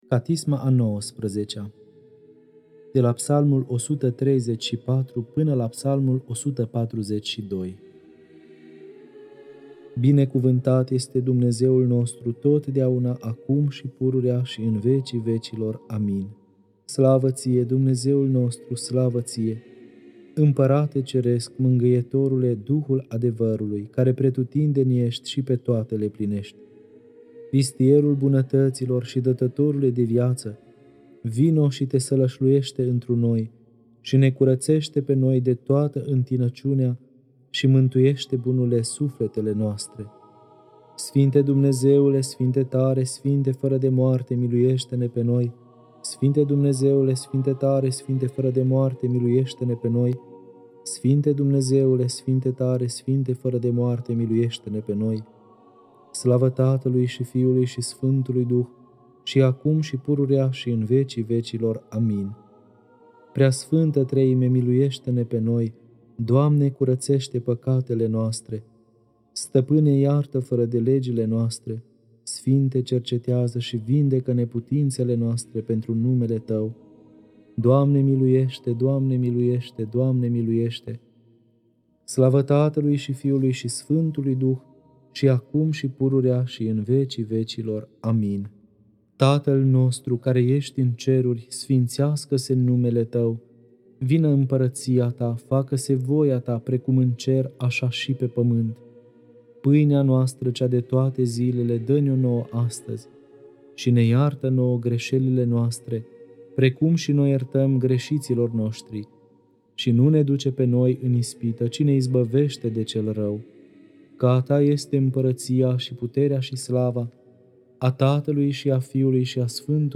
Catisma a XIX-a (Psalmii 134-142) Lectura: Arhim.